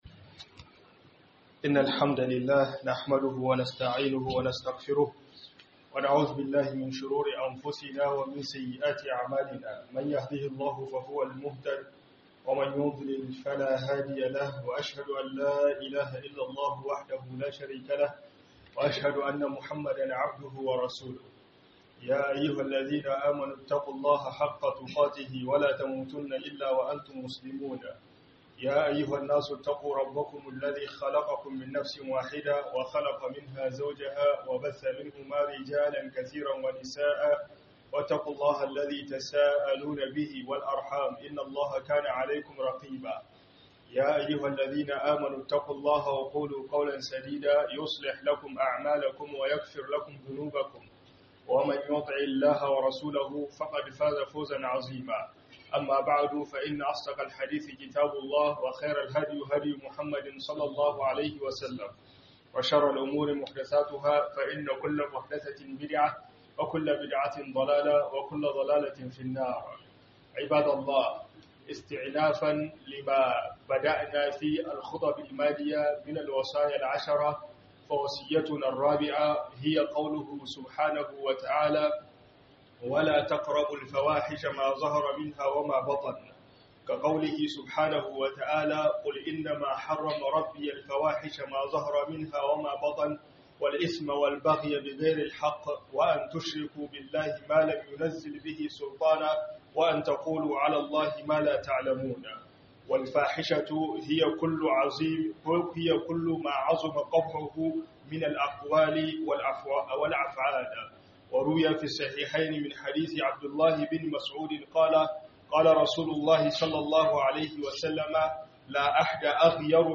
HUDUBA....